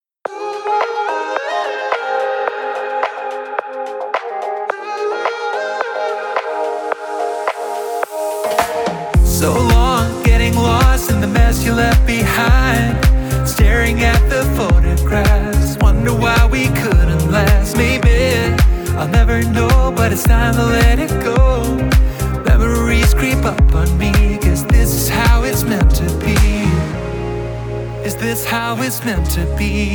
Жанр: Танцевальная музыка